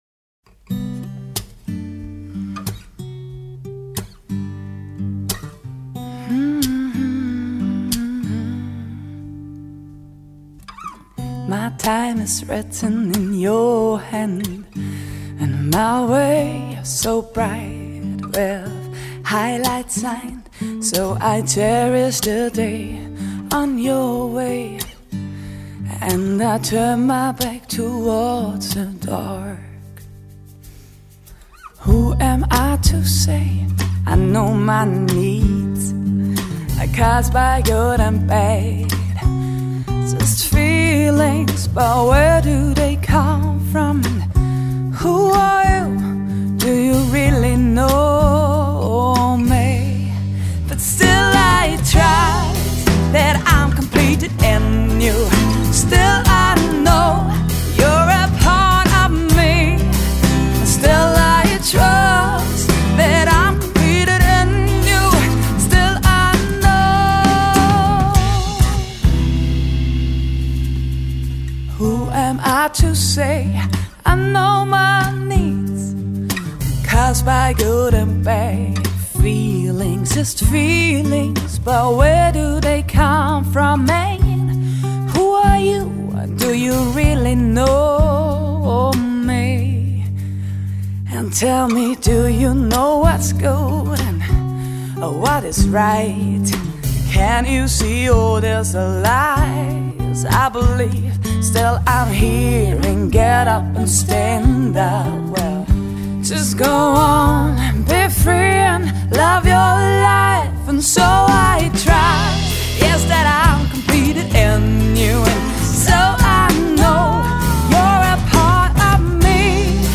Singer-songwriter.